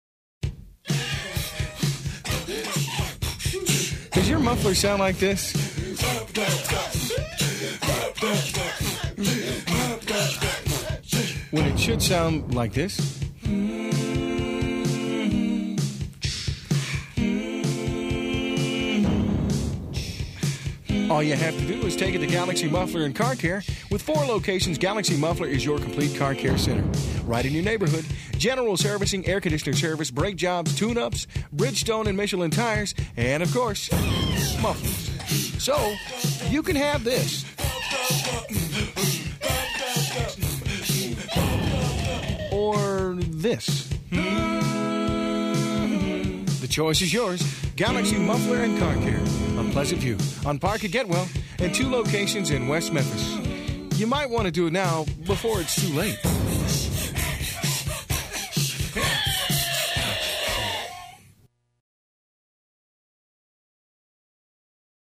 Radio-Mercury Award-winning spot for a muffler store from 1995, though the idea is just as fresh today as it was then.